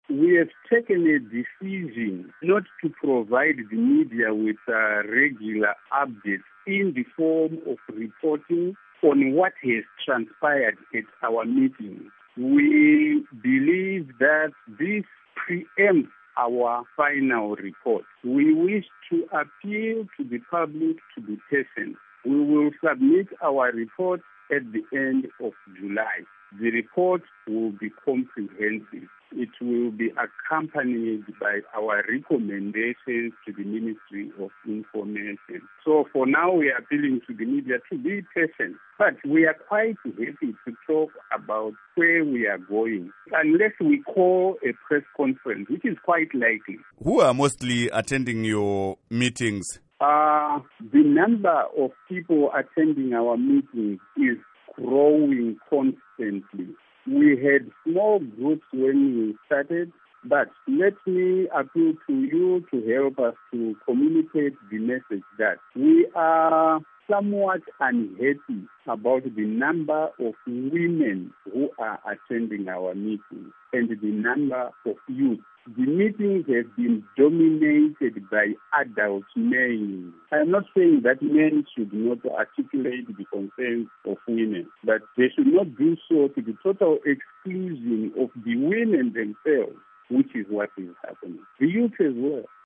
Interview With Geoff Nyarota